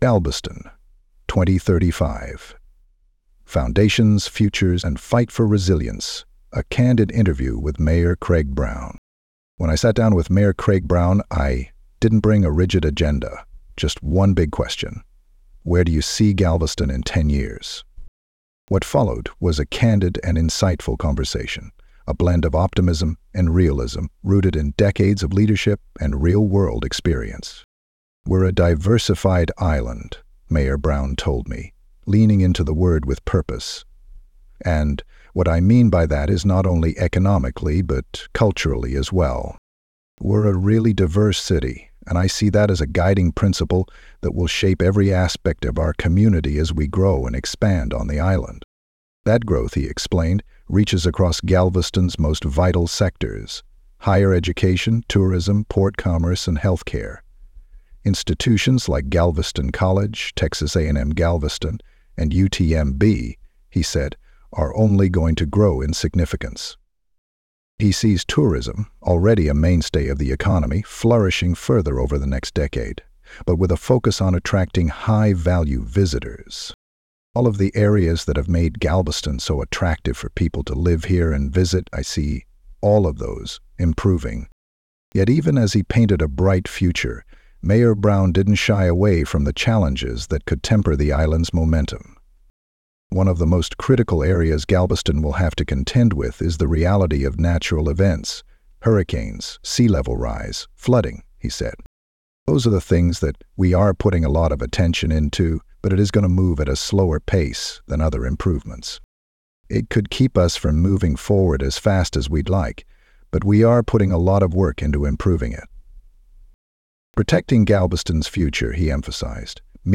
Here’s an audio version of the story, read in my own words.